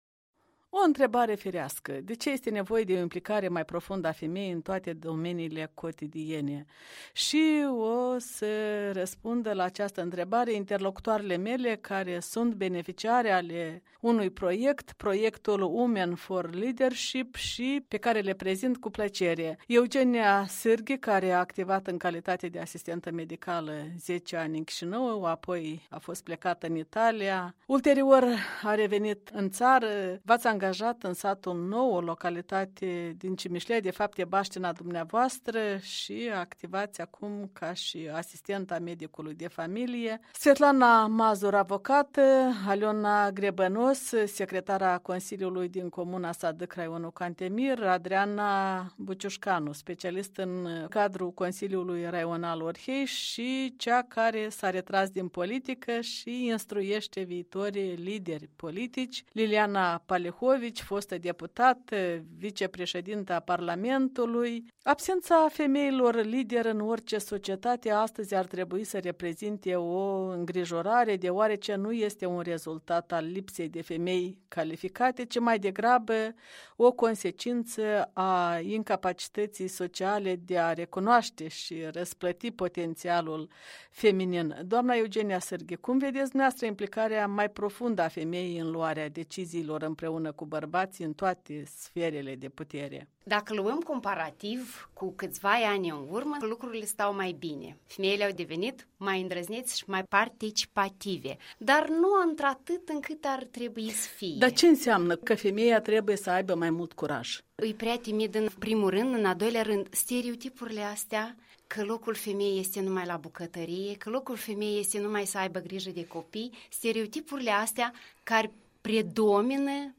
Masă rotundă în studioul din Chișinău al Europei Libere.